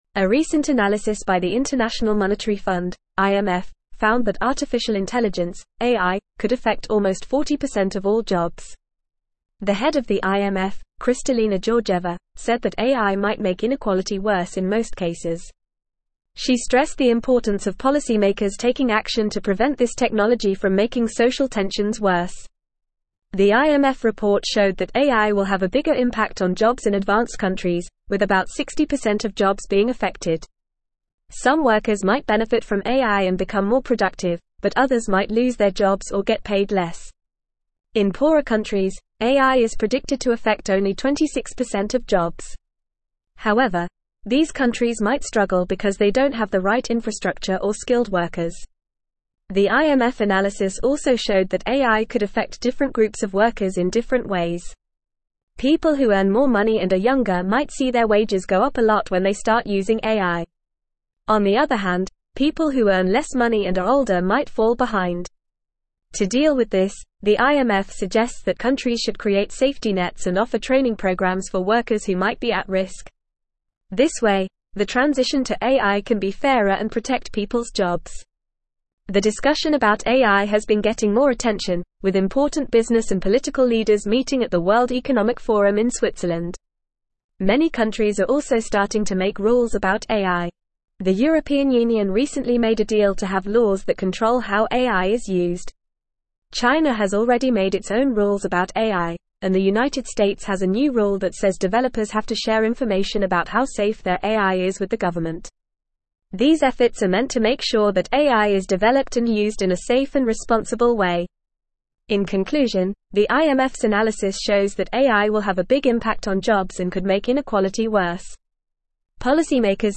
Fast
English-Newsroom-Upper-Intermediate-FAST-Reading-AIs-Impact-on-Jobs-and-Inequality-IMF-Analysis.mp3